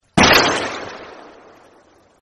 Gunshot Play Sound